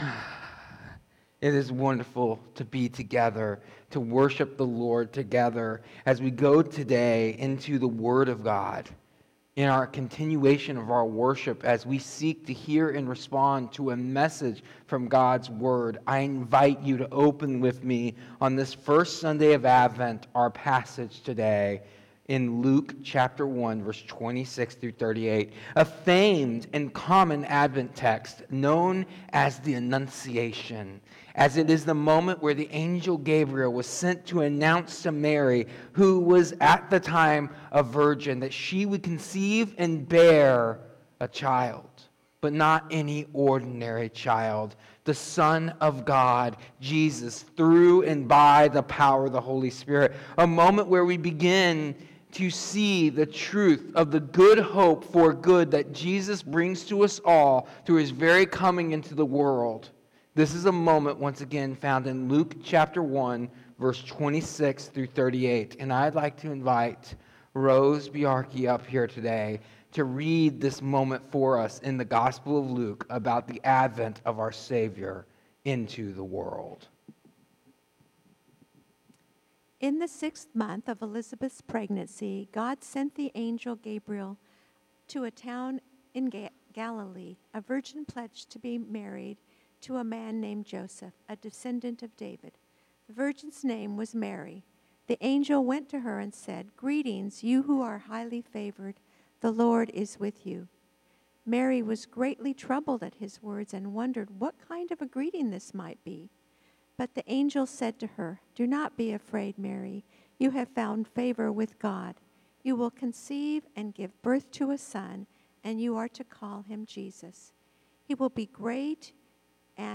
This sermon reflects on the first Sunday of Advent, the Sunday of Hope, through Luke 1:26–38, the announcement of Jesus’ conception.